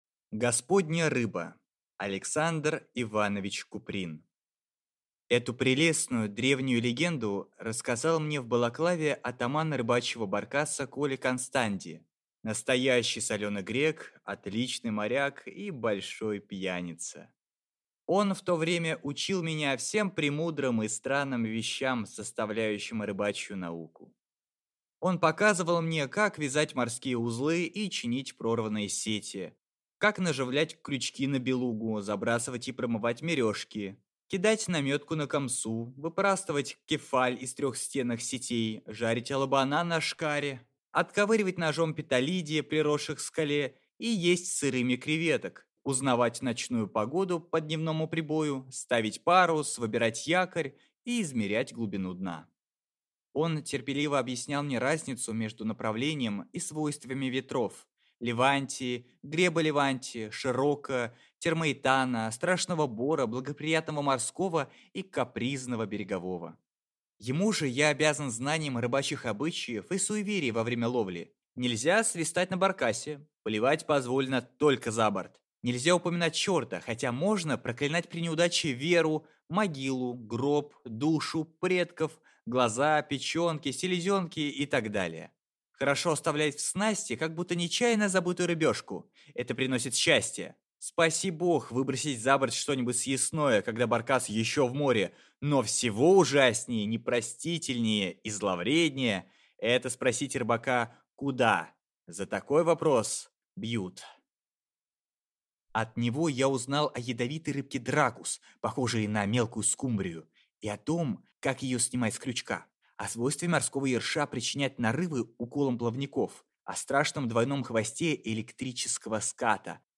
Аудиокнига Господня рыба